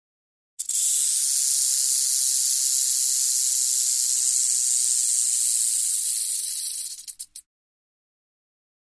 Звуки змей